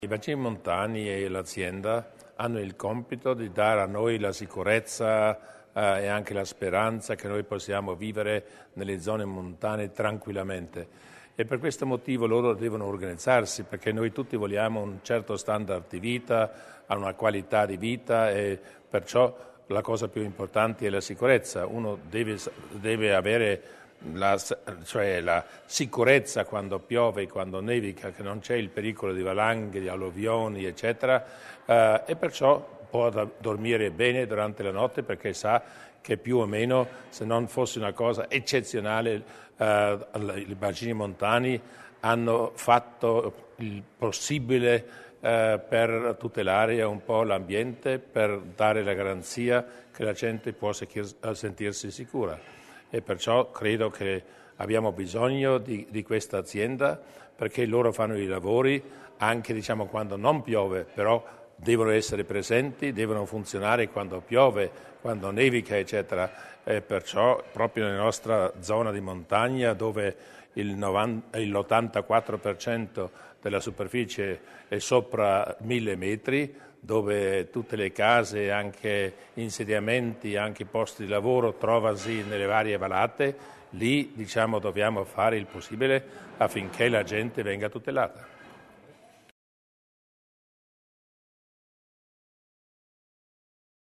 Celebrazione a Bressanone per i 125 anni dei Bacini montani